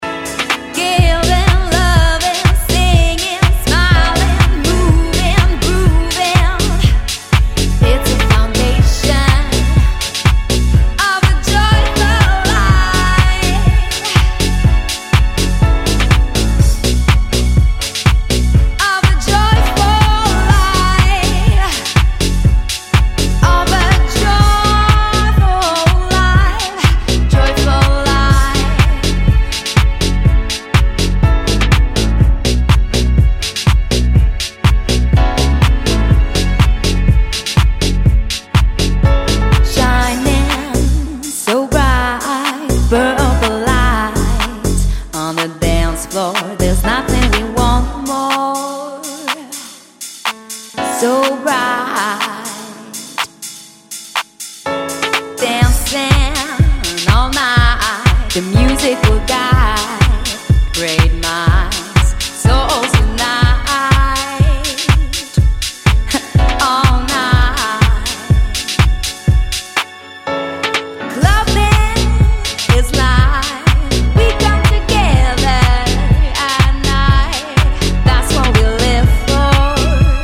packed with soul and delivered with a weighty bottom end